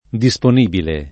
[ di S pon & bile ]